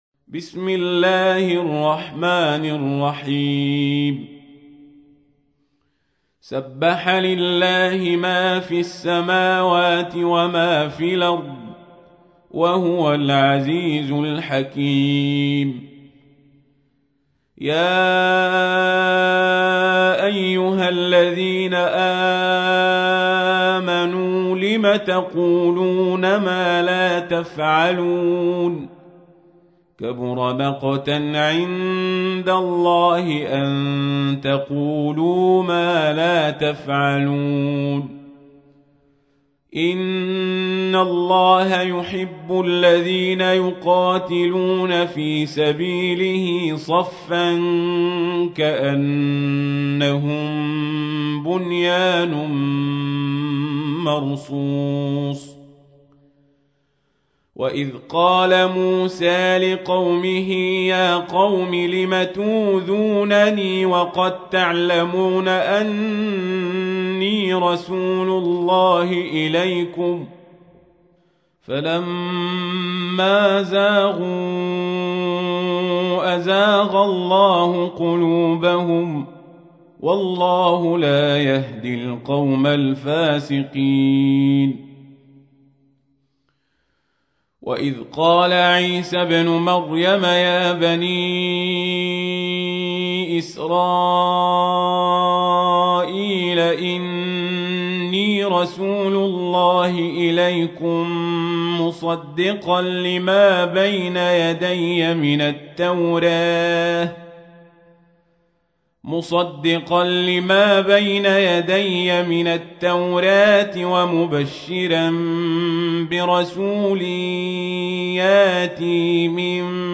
تحميل : 61. سورة الصف / القارئ القزابري / القرآن الكريم / موقع يا حسين